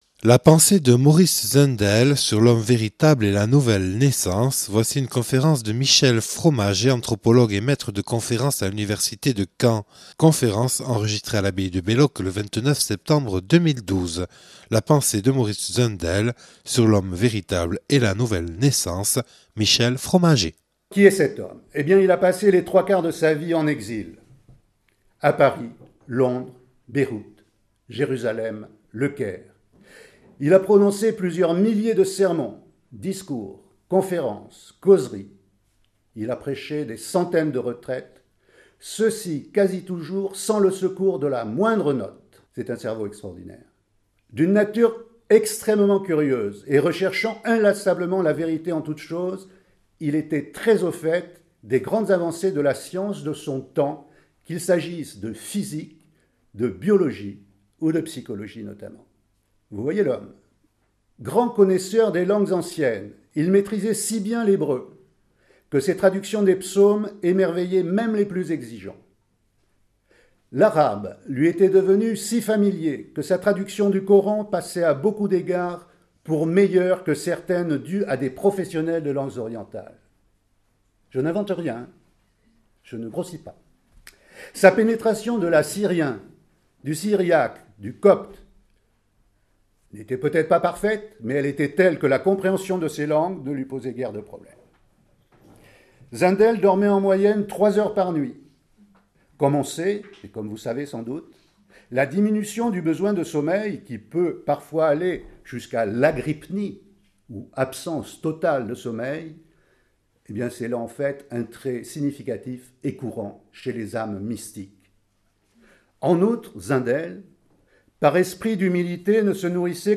(Enregistrée à l’abbaye de Belloc le 24/05/2012).